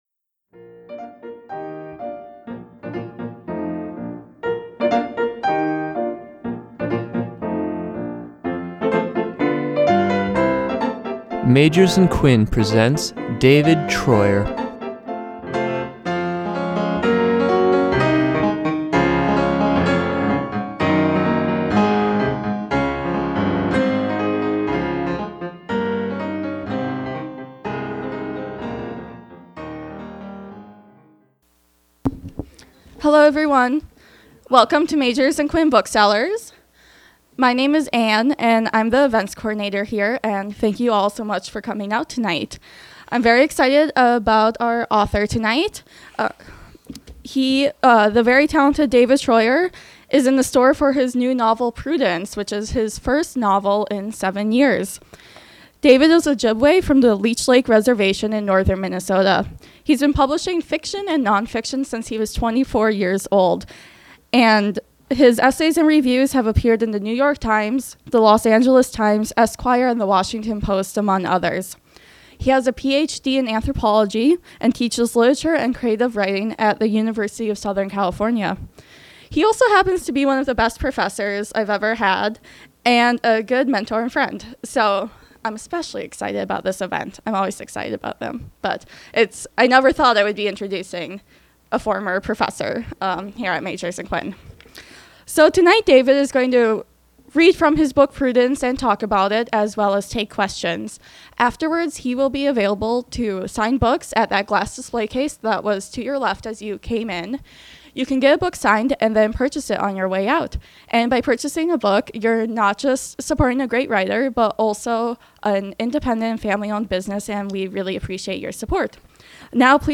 David Treuer reads and discusses his novel Prudence, set in rural Minnesota in the middle of WWII.
In February 2015, we welcomed David Treuer back to Minnesota for a reading of his novel Prudence.